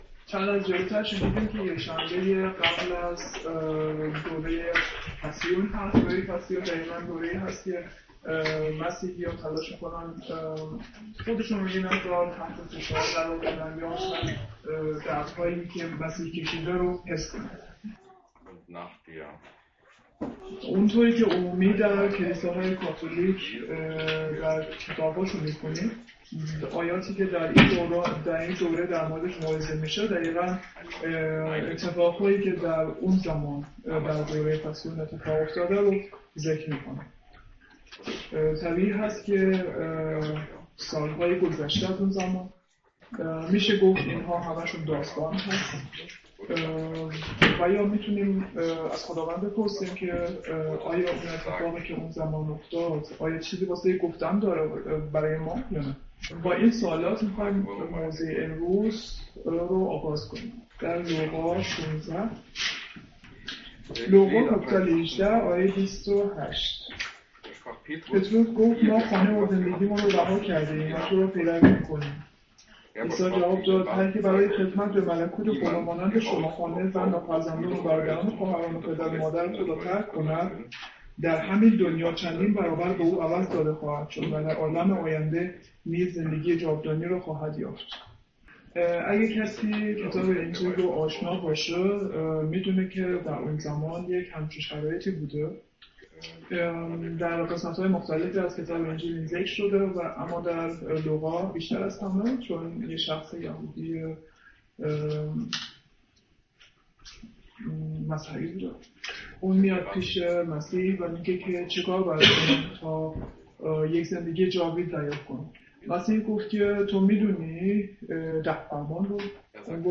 Übersetzung in Farsi